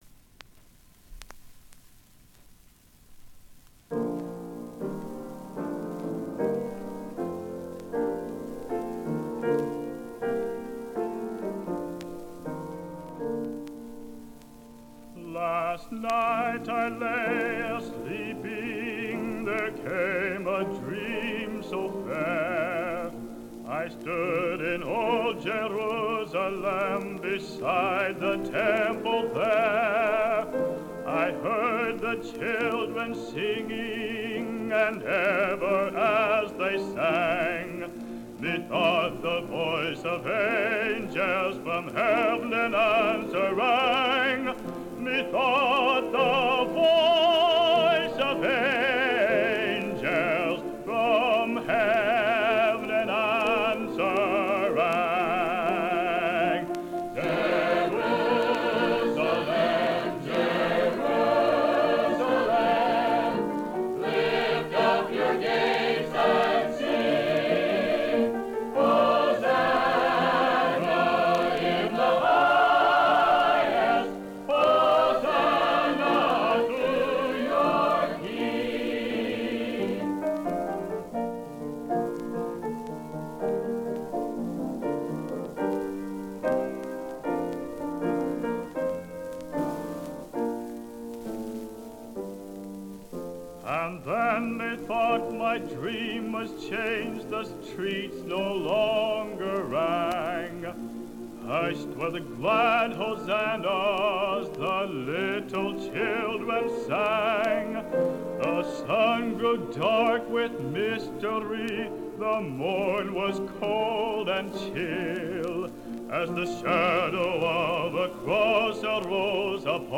Soloist